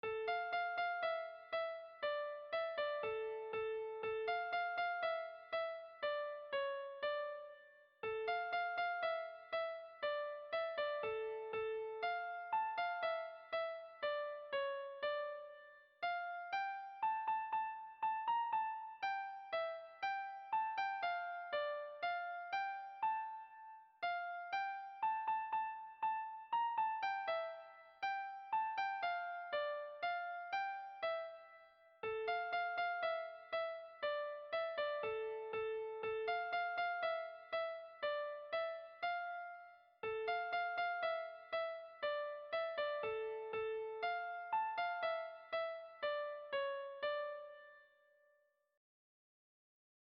Kontakizunezkoa
Bertsolaria
Hamabiko handia (hg) / Sei puntuko handia (ip)
10/8A/10/8A/10/8A/10/8A/10/8A/10/8A